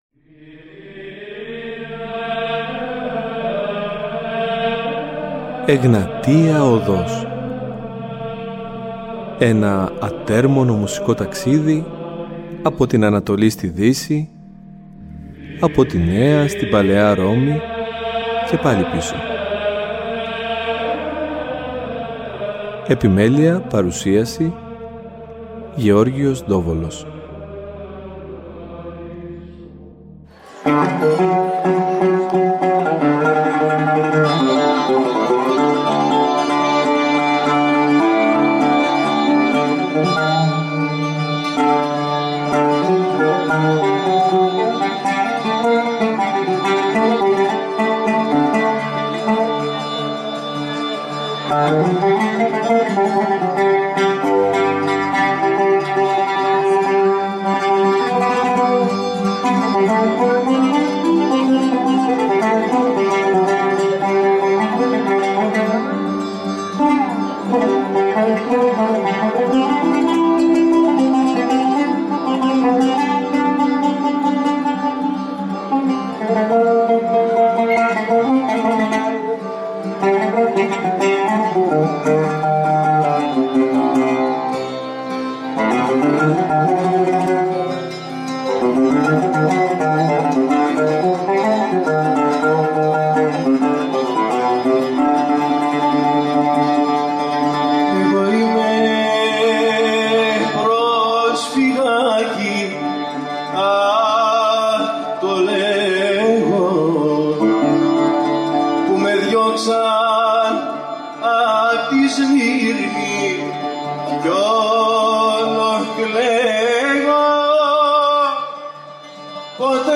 Στο πλαίσιο του δίπτυχου αφιερώματός μας, θα έχουμε την χαρά να τον ακούσουμε να τραγουδάει, να ψάλλει, μα κυρίως να μιλά για την μέχρι τούδε πορεία του και τα βιώματά του.